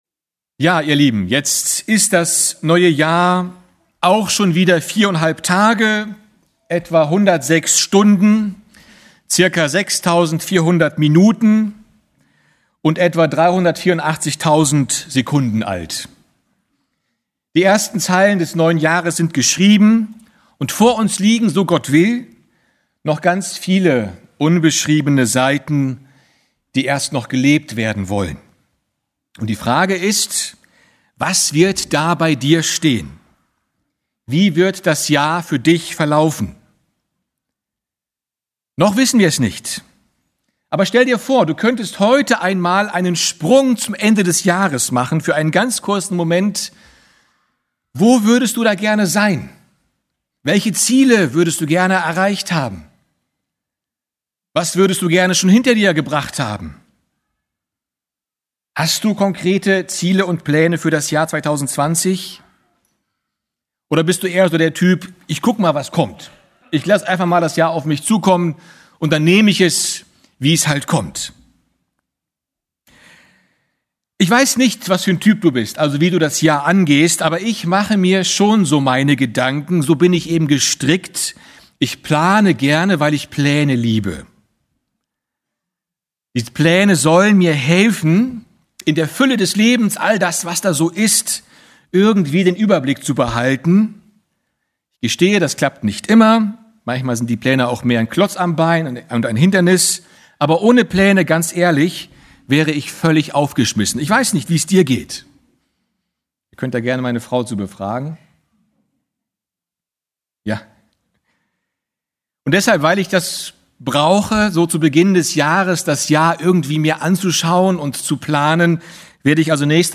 Einzelpredigten